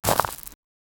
footsteps-of-a-roman-armo-owapyyws.wav